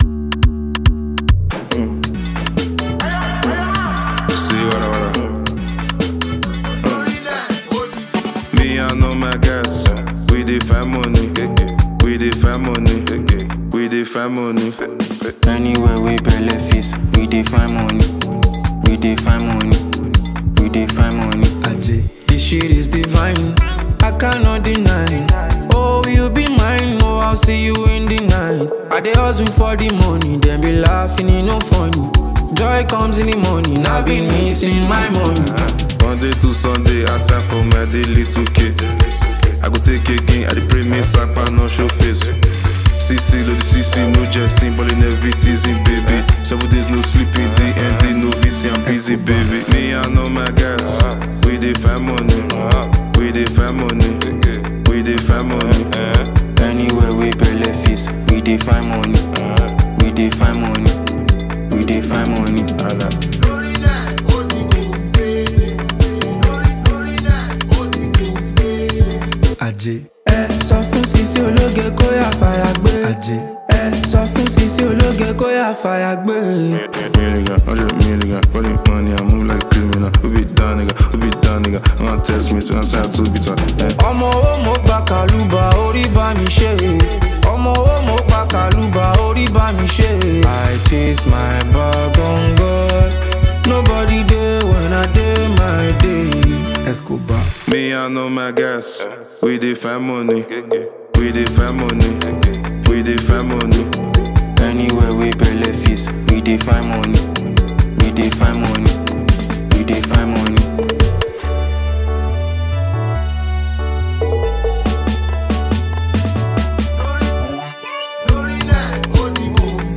Scorching Talented Nigerian Singer